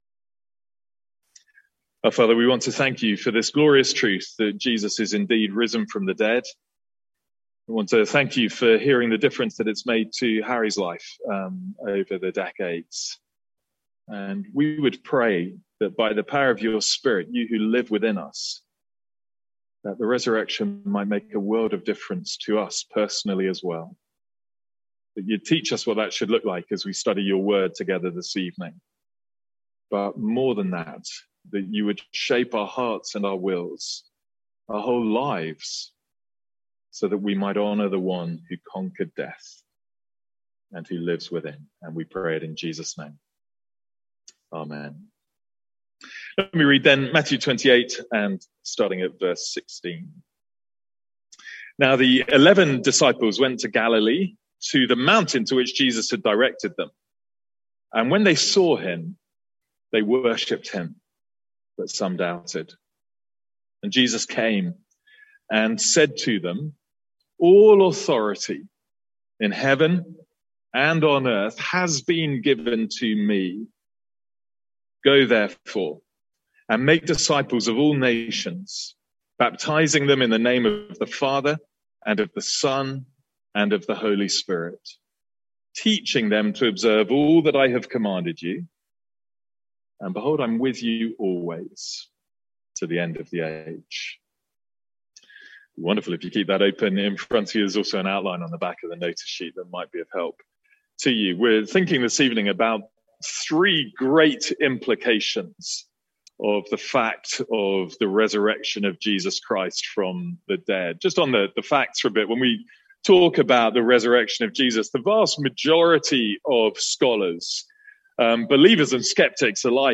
From our Easter Sunday evening service.